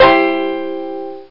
Banjo Maj Up Sound Effect
Download a high-quality banjo maj up sound effect.
banjo-maj-up.mp3